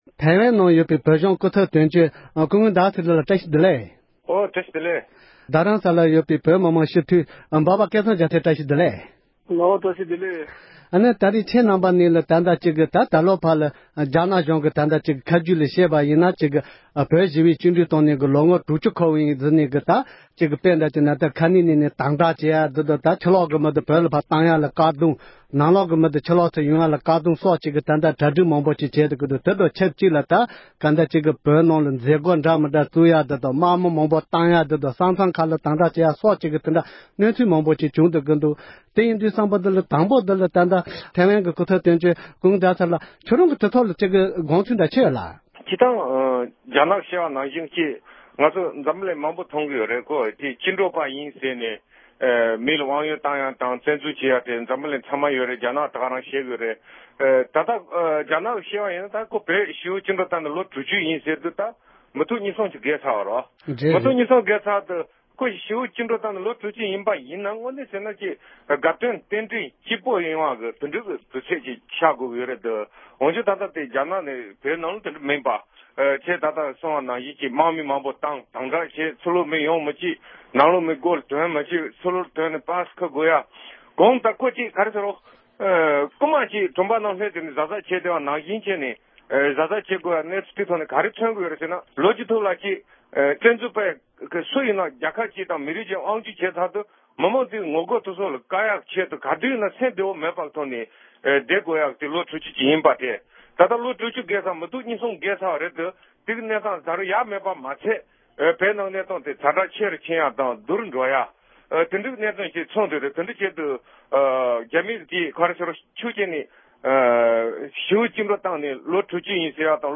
རྒྱ་ནག་གཞུང་གིས་བོད་ཞི་བའི་བཅིངས་གྲོལ་བཏང་ནས་ལོ་ངོ་དྲུག་བཅུ་འཁོར་བ་ཞེས་པའི་དུས་དྲན་སྐོར་བཀའ་མོལ་ཞུས་པ།